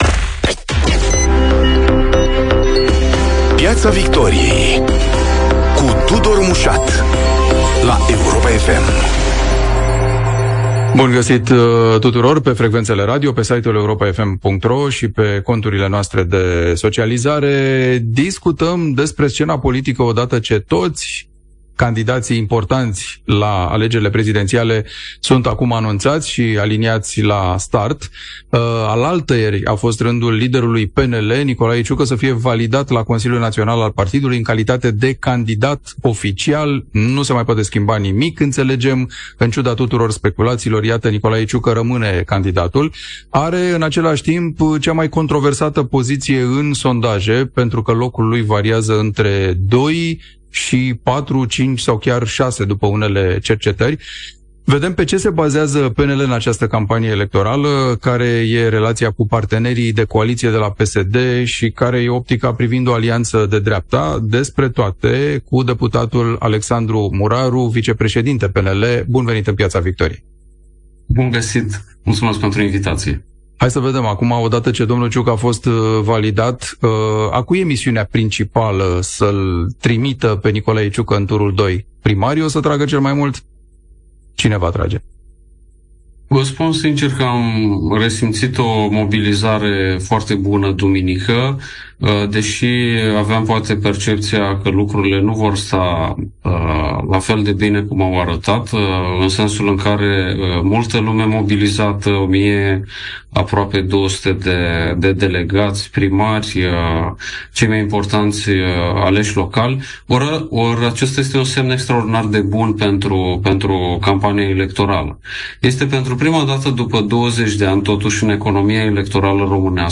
Invitat este fostul ministru al educației și rector SNSPA, Remus Pricopie | VIDEO